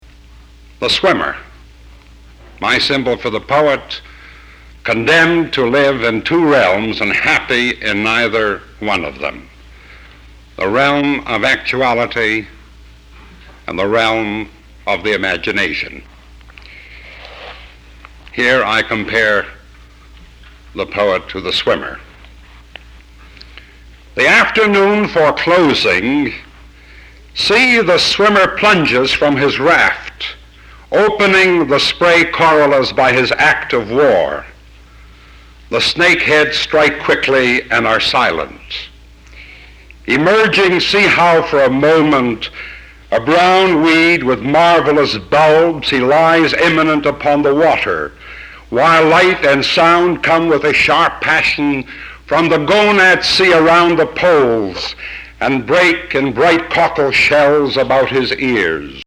Irving Layton is a guest of the SGW Poetry Reading Series on March 18, 1967.